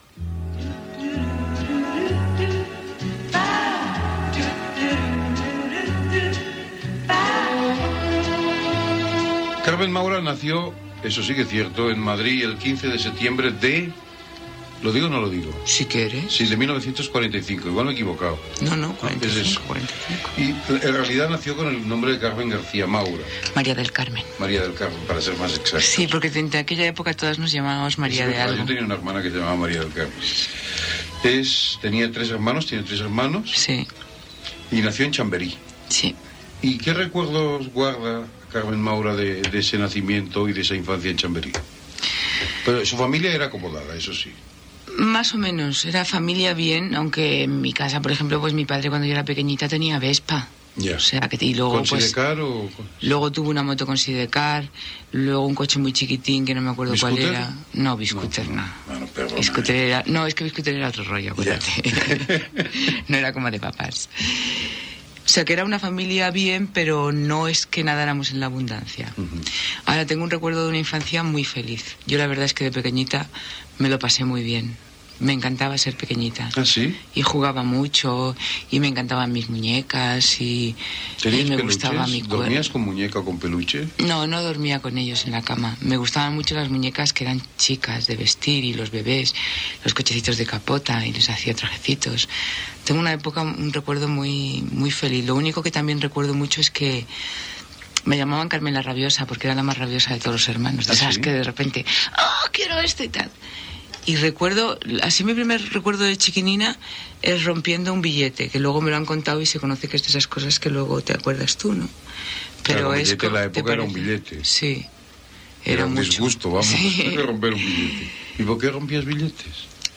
Entrevista a l'actriu Carmen Maura que recorda la seva infantesa i joventut